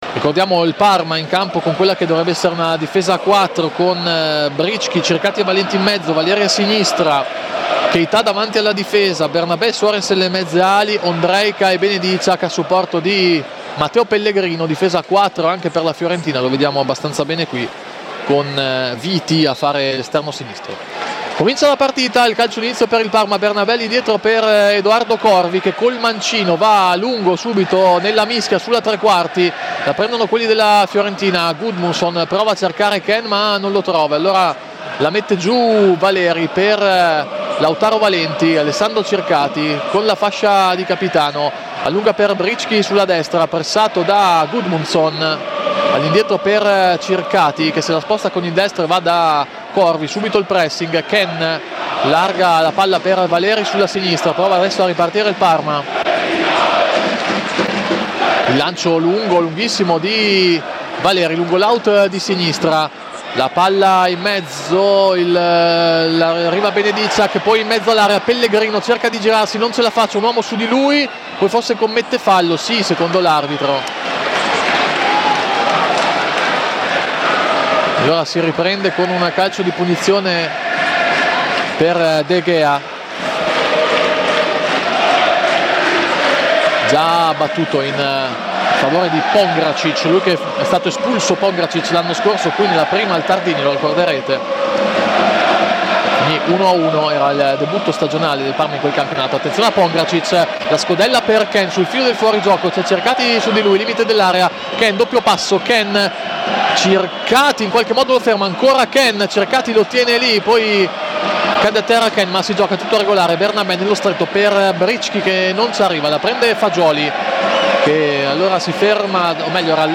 Radiocronache Parma Calcio Parma - Fiorentina 1° tempo - 27 dicembre 2025 Dec 27 2025 | 00:46:23 1x 00:00 / 00:46:23 Subscribe Share RSS Feed Share Link Embed